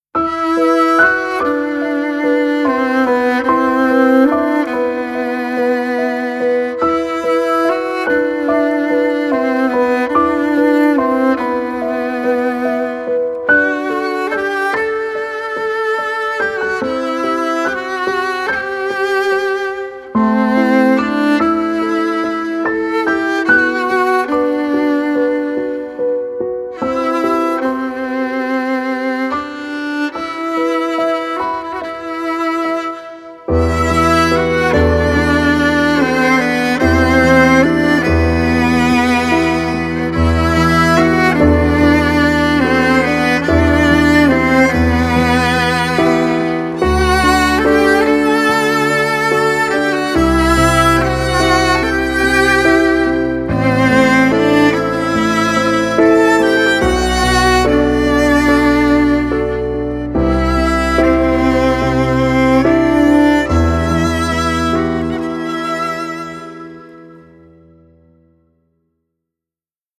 ژانر: لایت بی کلام